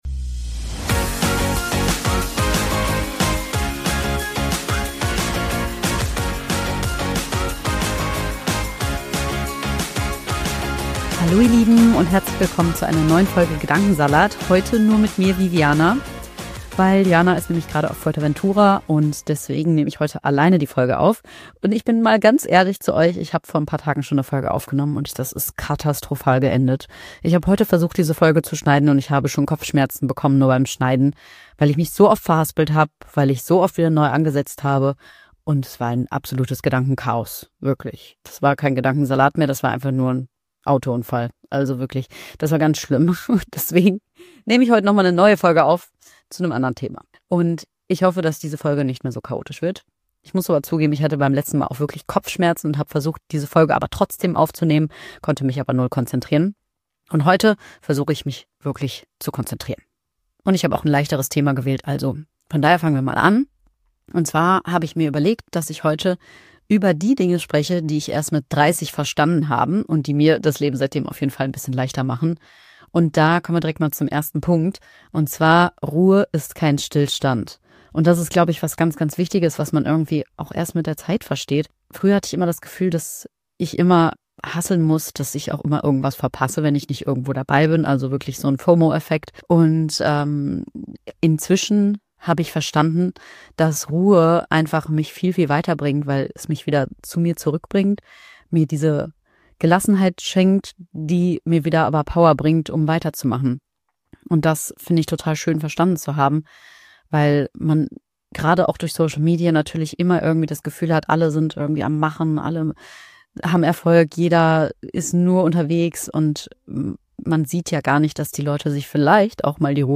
Es geht um Ruhe, die kein Stillstand ist, um Vertrauen ins eigene Bauchgefühl, um Grenzen ohne Rechtfertigung und darum, warum Alleinsein manchmal genau das ist, was wir brauchen. Eine ehrliche, ruhige Folge zum Innehalten, Mitfühlen und Wiedererkennen, vielleicht ist auch für dich ein Gedanke dabei, der gerade genau richtig kommt.